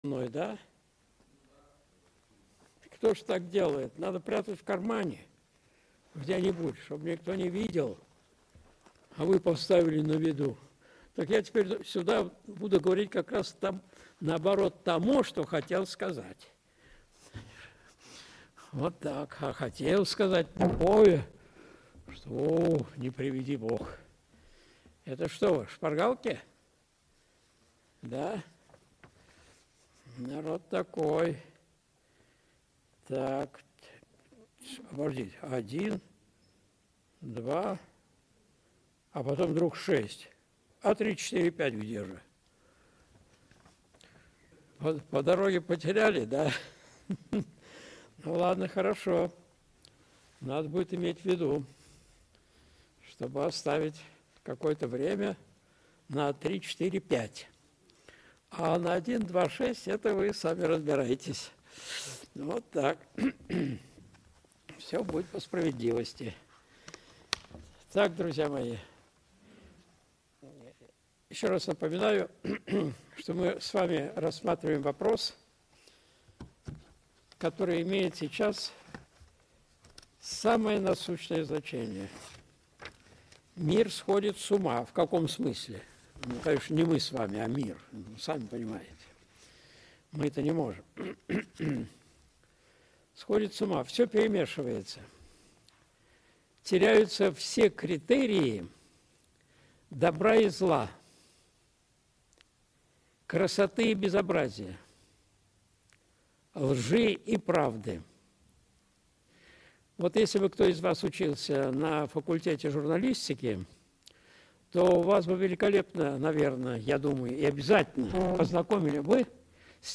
Христианские истины, которых никогда не было в истории религии (Часть 2) (Прямая трансляция, курс по Апологетике в МДА, 07.02.2025)
Видеолекции протоиерея Алексея Осипова